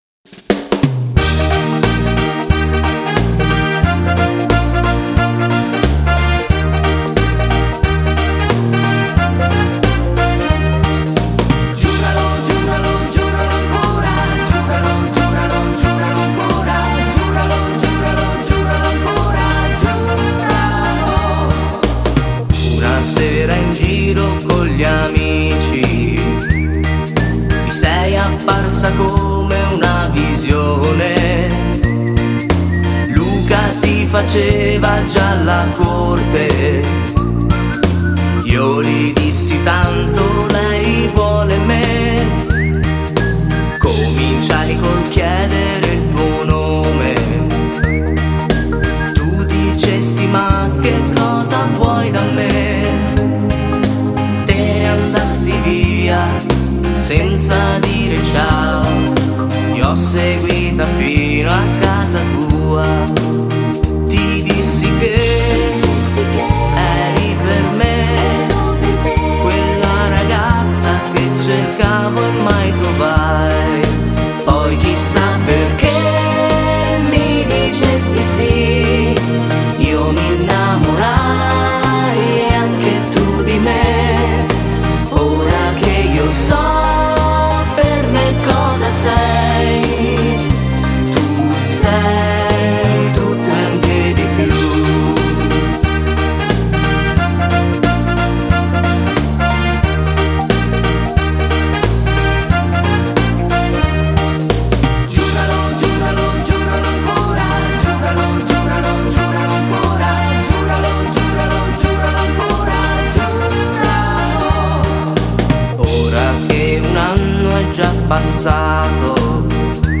Genere: Fox trot